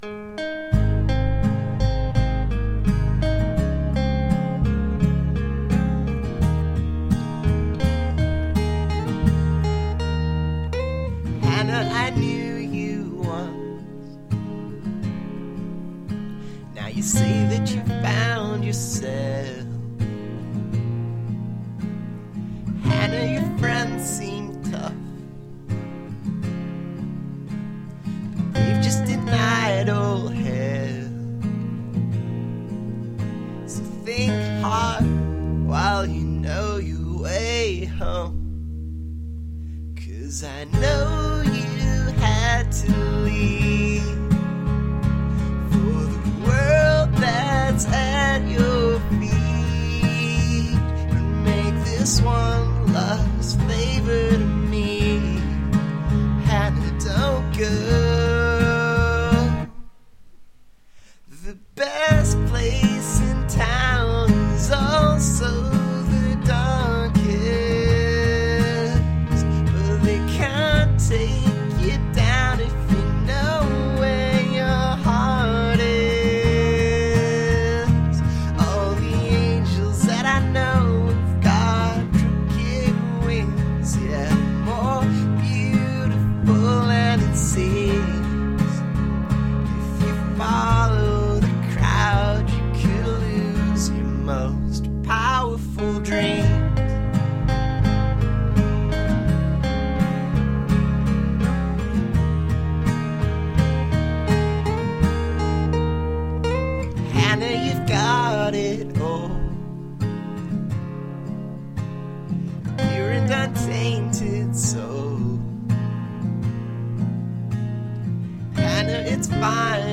(Capo 1)